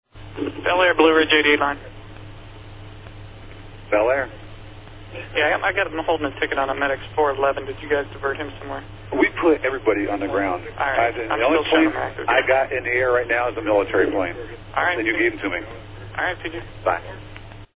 Here is how that order played out in the airspace controlled by Cleveland Center that included Gofer 06.